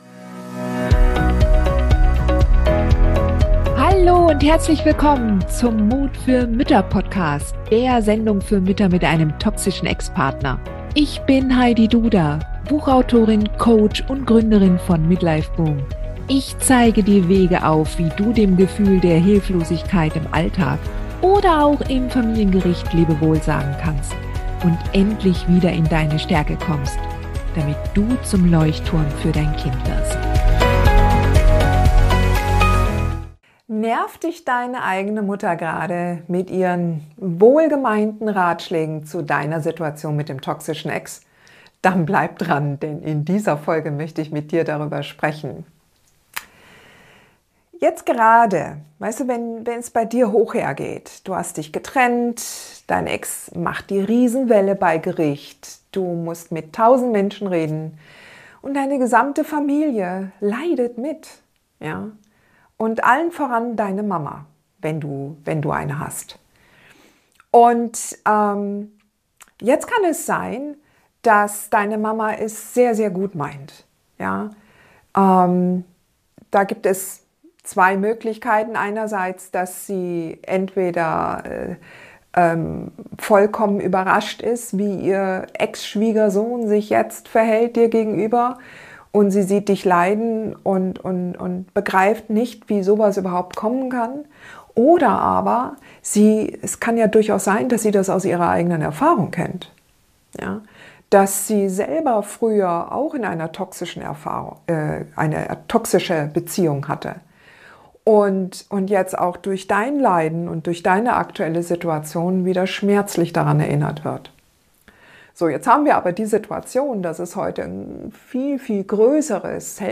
#242: Interview: Wenn die Next noch toxischer als der KV ist – Mut für Mütter - Beende die toxische Beziehung zum narzisstischen Kindsvater und definiere sie neu!